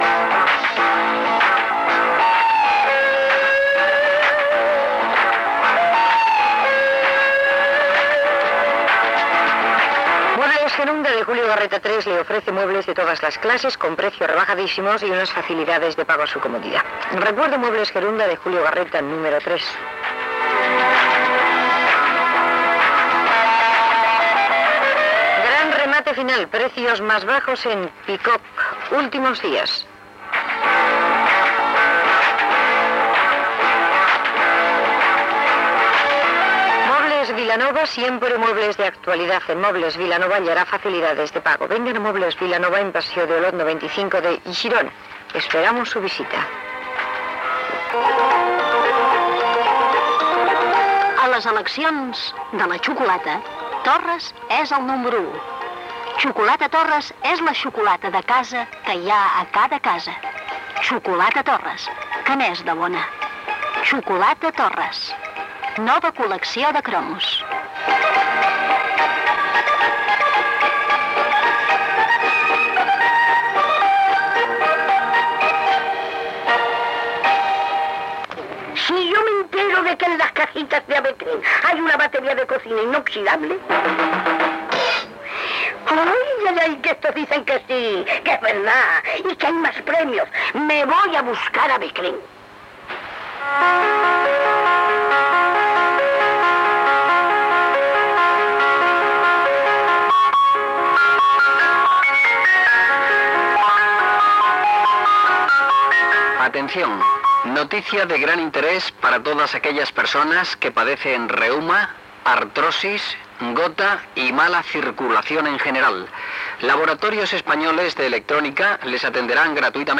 Publicitat llegida i alguns anuncis. Careta del programa, presentació, sumari de continguts i tema musical
Musical